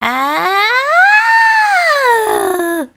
Worms speechbanks
Bungee.wav